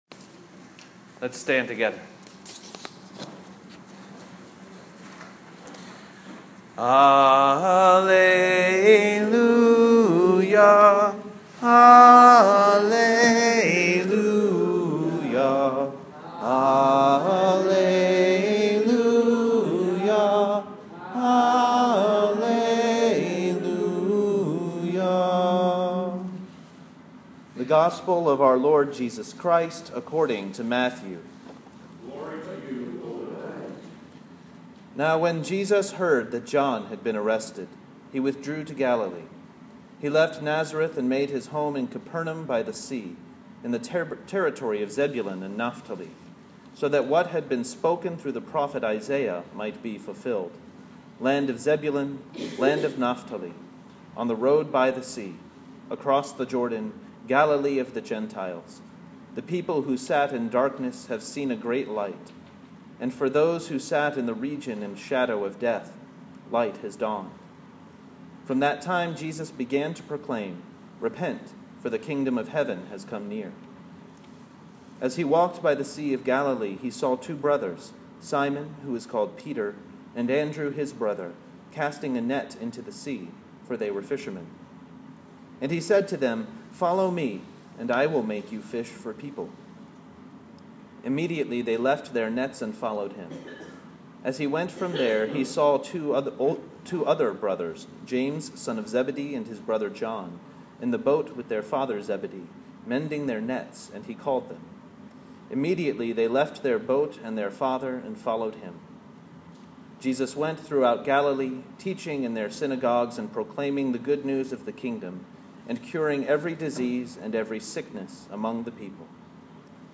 sermon-ordinary-3-a-2017.m4a